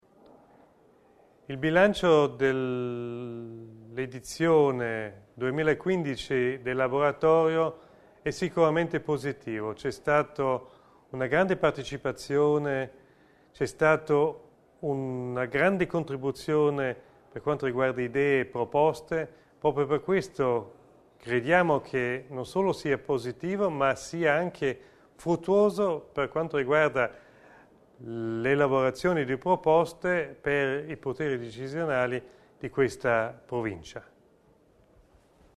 Intervista Michl Ebner